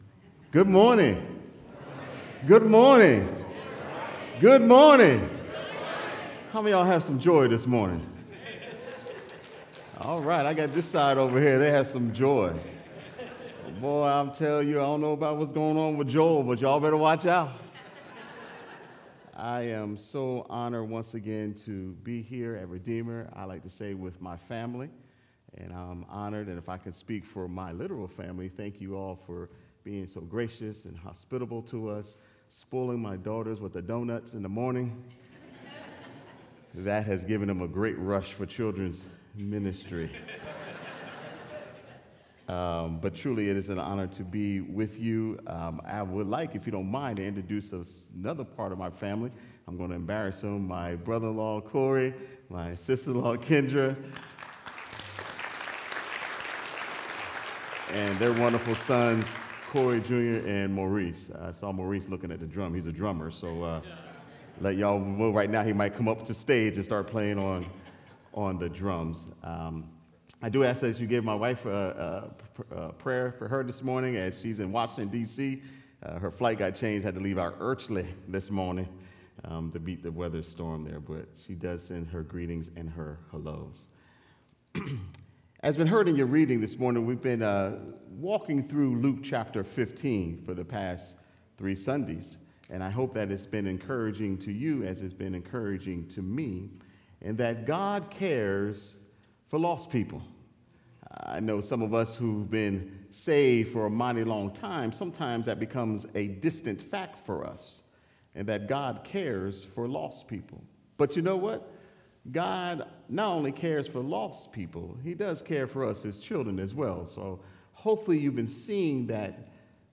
Sermon3.3.19.mp3